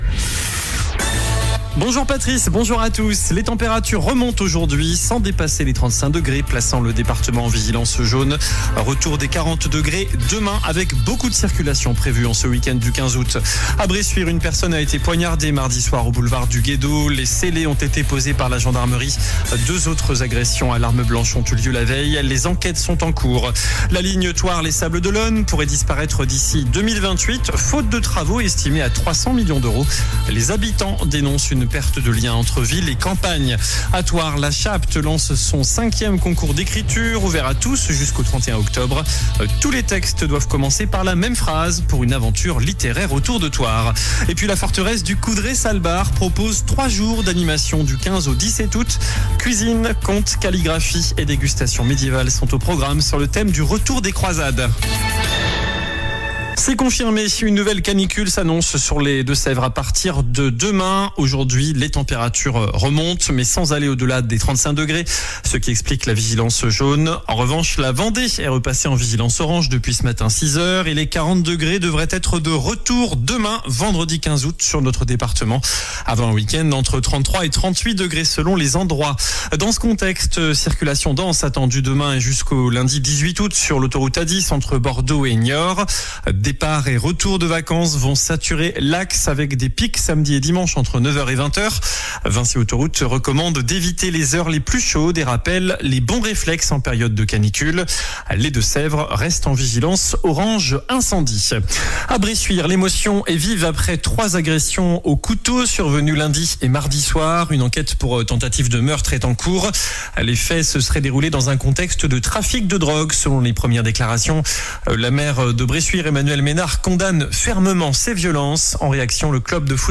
JOURNAL DU JEUDI 14 AOÛT ( MIDI )